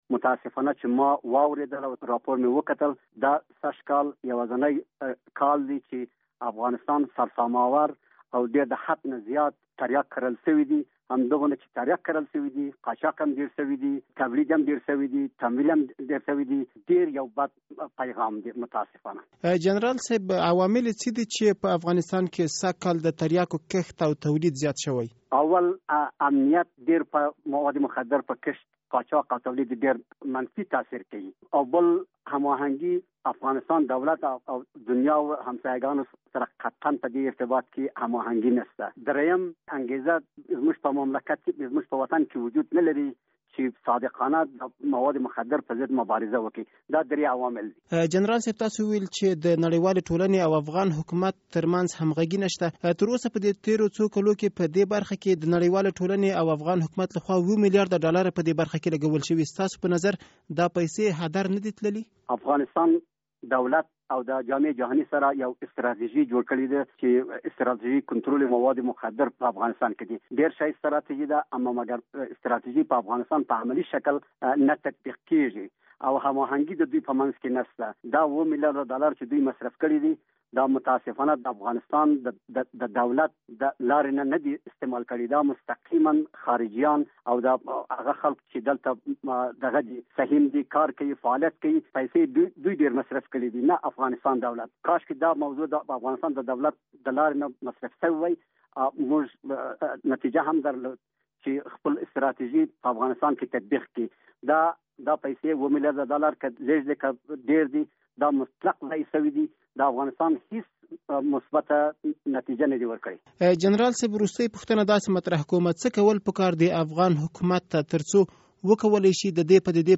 له پخواني وزیر خدایداد سره مرکه